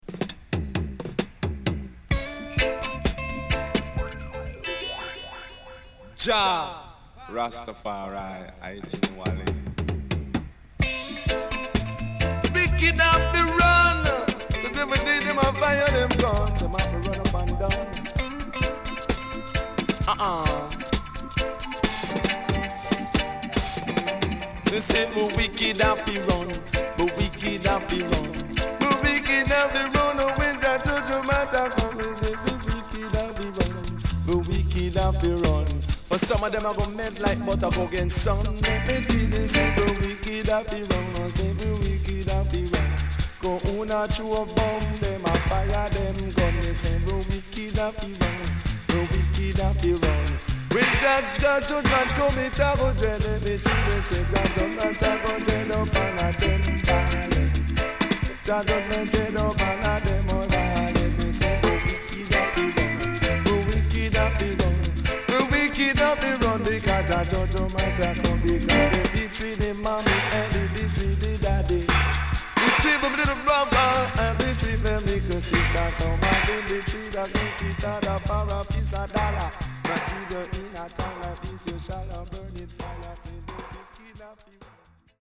SIDE A:少しチリノイズ入ります。
SIDE B:少しチリノイズ入ります。